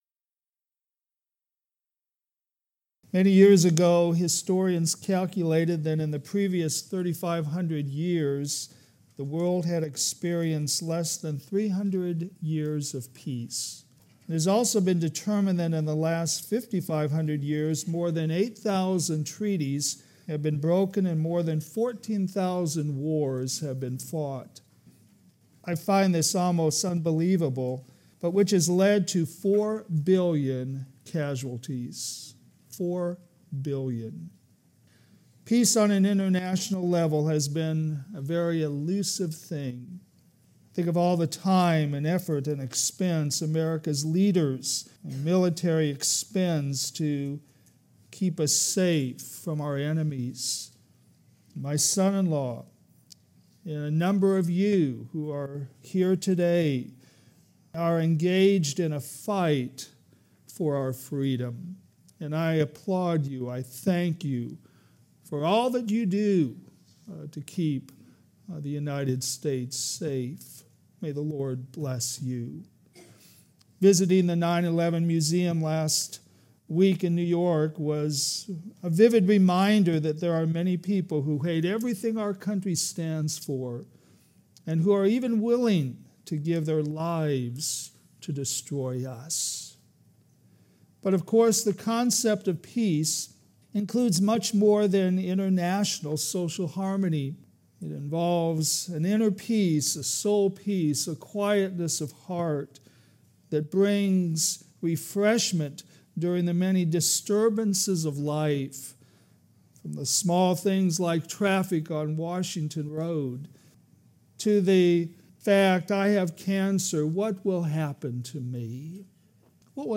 All Sermons - Westside Baptist Church